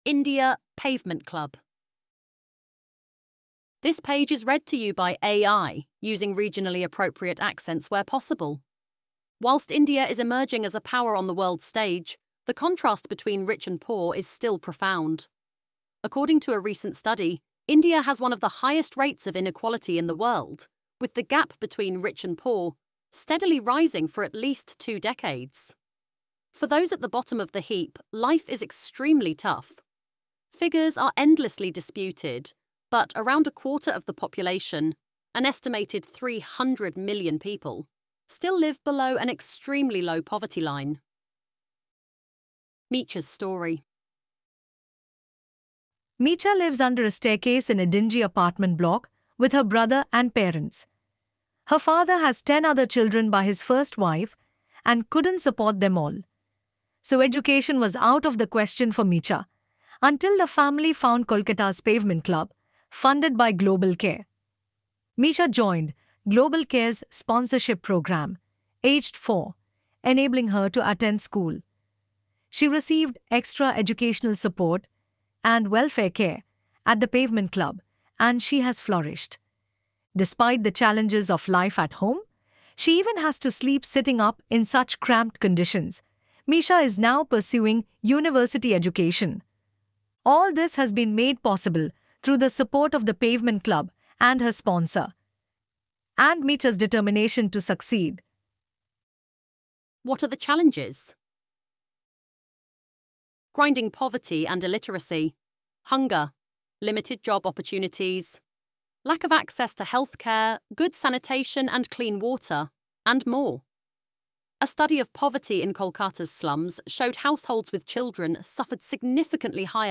ElevenLabs_India_Pavement_Club.mp3